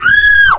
SCREAMF1.mp3